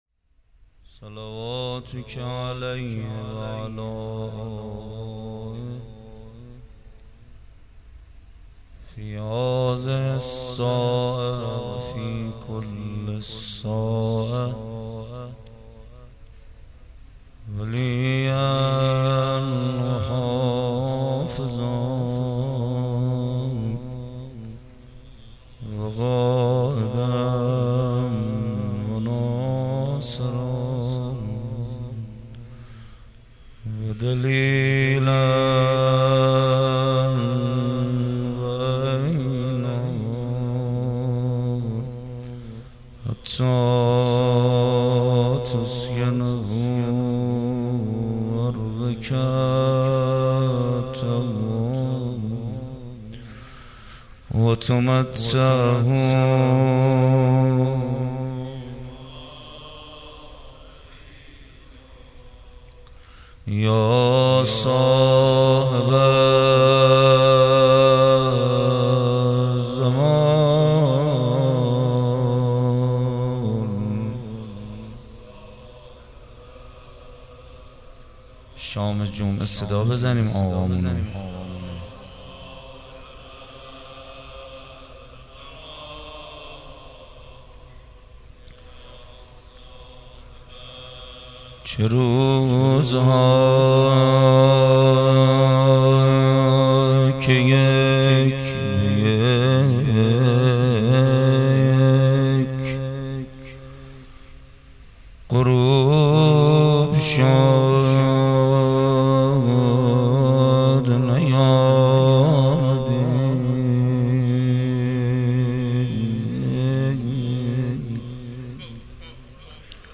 مداحی شب دوم محرم 1399 با نوای کربلایی محمد حسین پویان‌فر